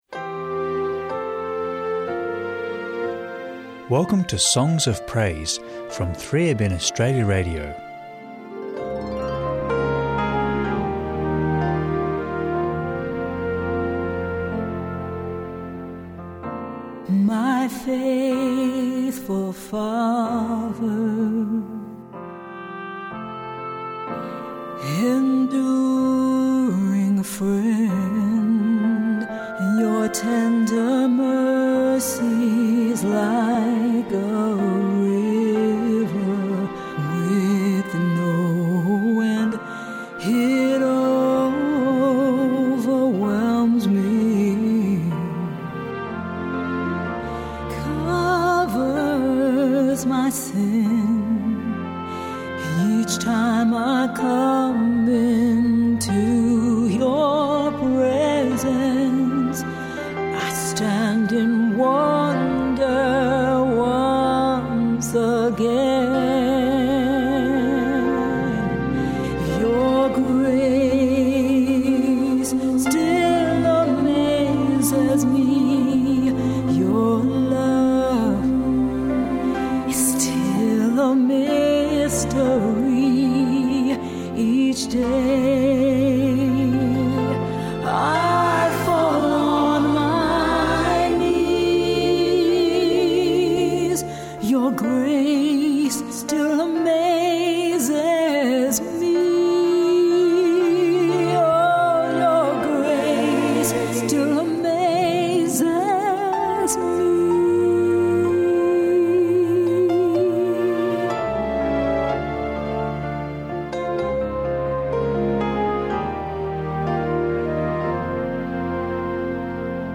Enjoy uplifting Christian hymns and worship music